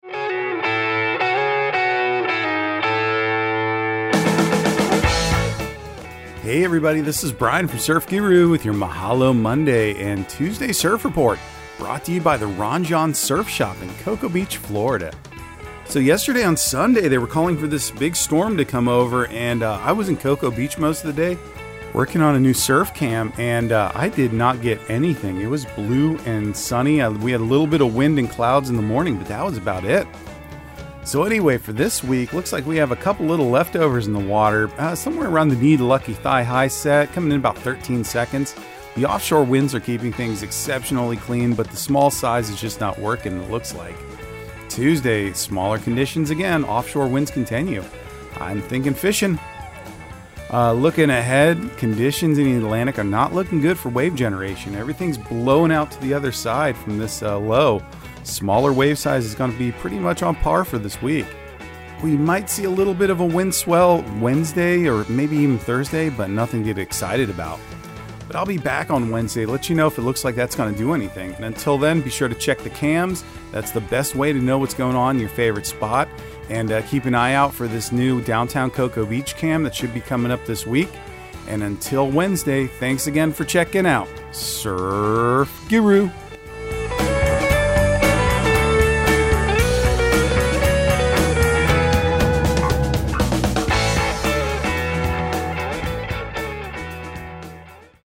Surf Guru Surf Report and Forecast 05/01/2023 Audio surf report and surf forecast on May 01 for Central Florida and the Southeast.